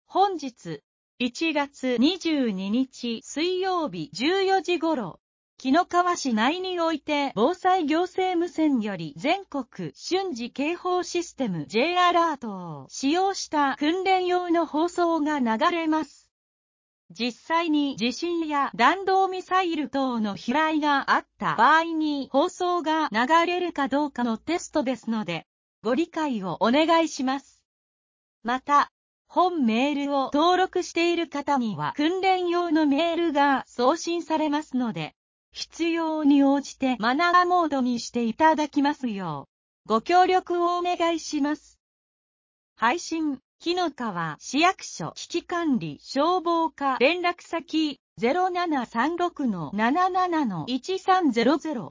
本日、１月２２日（水）１４時頃、紀の川市内において防災行政無線より全国瞬時警報システム（Ｊアラート）を使用した訓練用の放送が流れます。実際に地震や弾道ミサイル等の飛来があった場合に放送が流れるかどうかのテストですので、ご理解をお願いします。